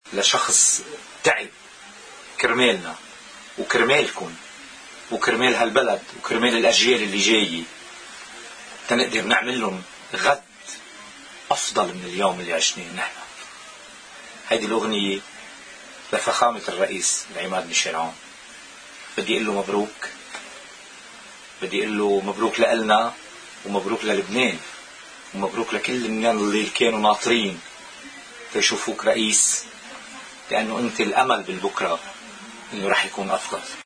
يقول الشاعر نزار فرنسيس لقناة الـ”OTV” بمناسبة وصول العماد عون رئيساً للجمهورية، وقال قبيل الإنتهاء من ترتيبات الإحتفال التي ستقام عشية يوم الإثنين: